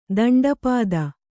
♪ daṇḍa pāda